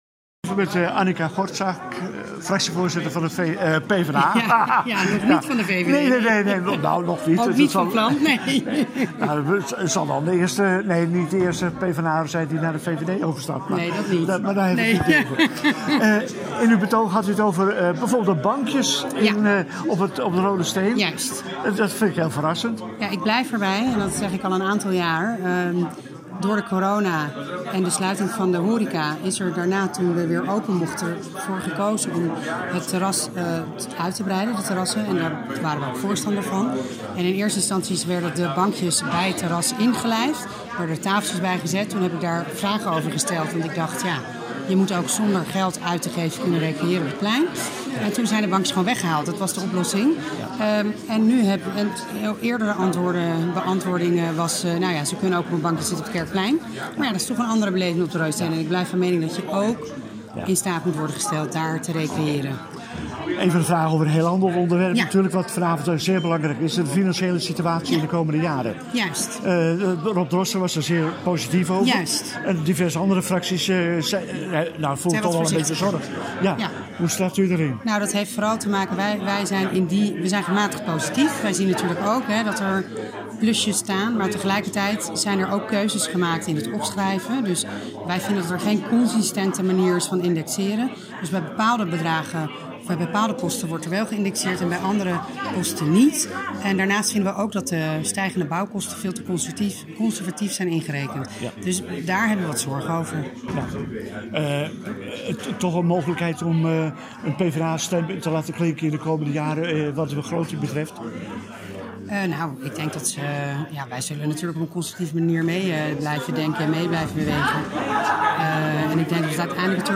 Raadsvergadering 21 juni 2022 (Audio interview met Arnica Gortzak (PvdA), Rob Droste (VVD), wethouder René Assendelft (HL)